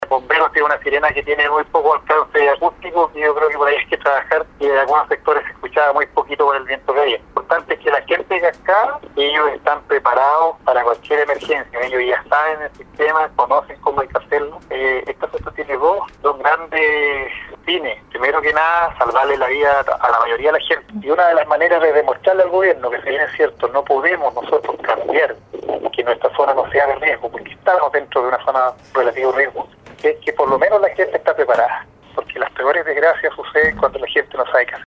Según comentó el concejal a Radio Sago, el ejercicio es fruto de un trabajo largo que vio luces hace ya 10 años y que era necesario aplicar entendiendo que la población se renueva y mueve, así también conocer tiempos de respuesta y las dificultades o necesidades por parte de los organismos de seguridad, como bomberos, a la hora de alertar a la población en una emergencia de erupción u otra.